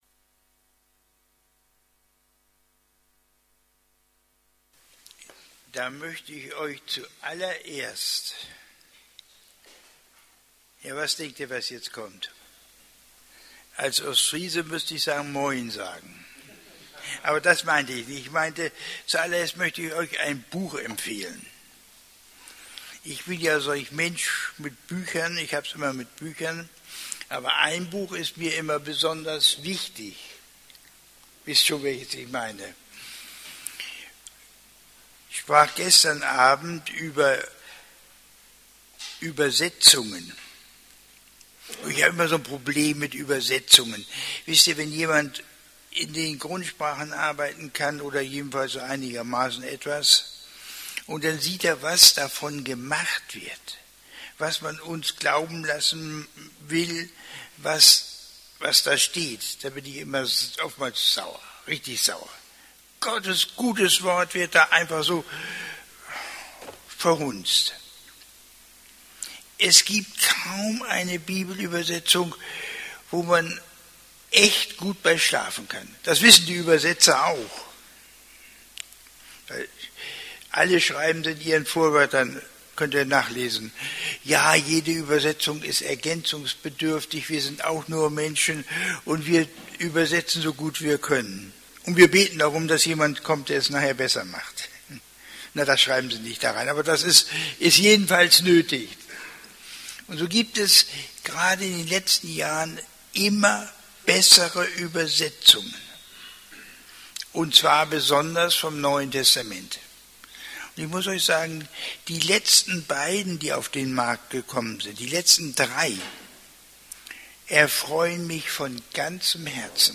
Dienstart: Andere Vorträge